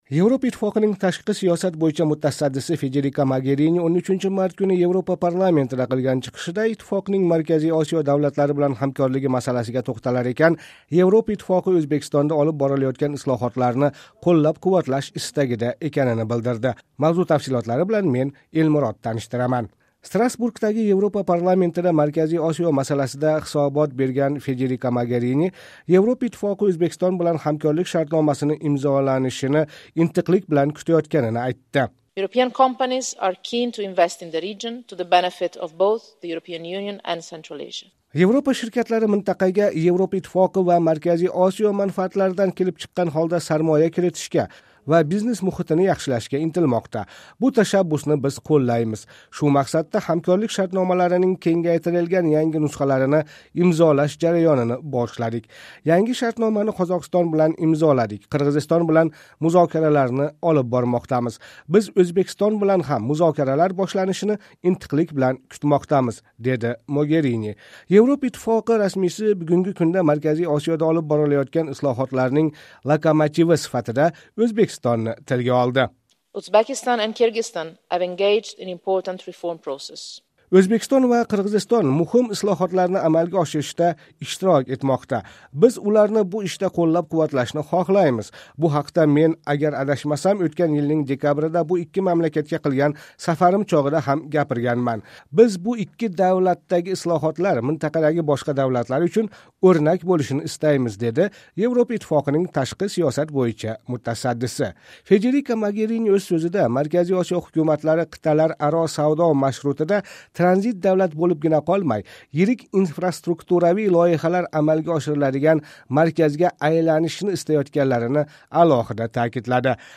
Yevropa Ittifoqining tashqi siyosat bo‘yicha mutasaddisi Federika Mogerini 13 mart kuni Yevropa parlamentida qilgan chiqishida ittifoqning Markaziy Osiyo davlatlari bilan hamkorligi masalasiga to‘xtalar ekan, Yevropa Ittifoqi O‘zbekistonda olib borilayotgan islohotlarni qo‘llab-quvvatlash istagida ekanini bildirdi.